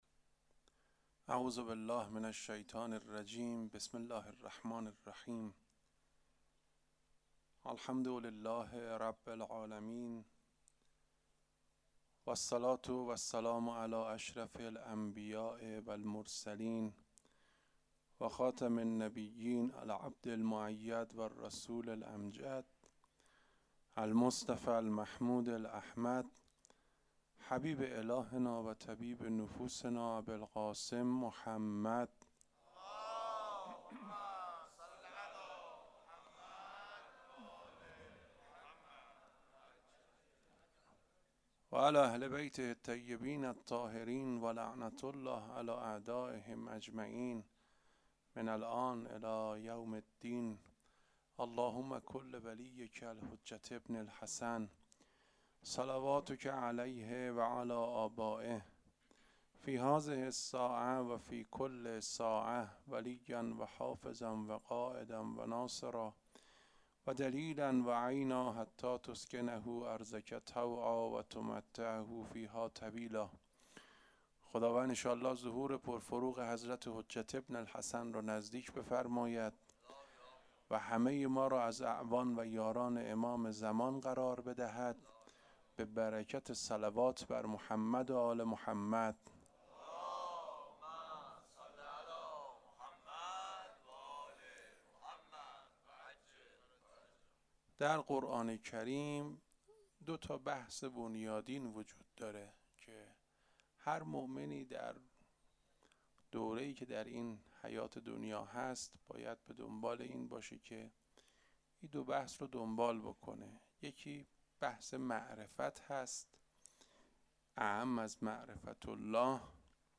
سخنرانی
مراسم عزاداری شب ششم فاطمیه ۱۴۴۳